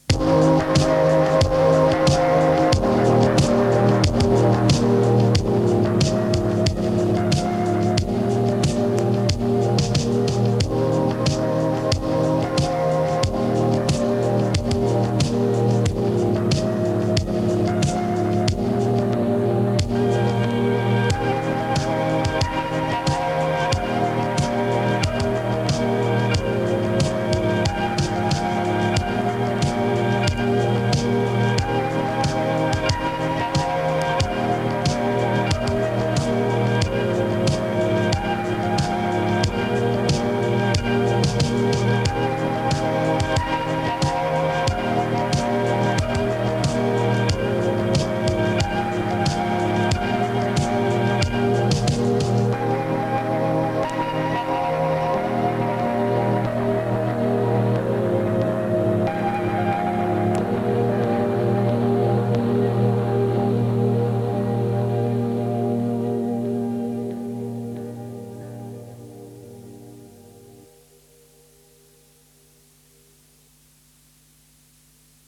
steady and gorgeous melancholy synth banger.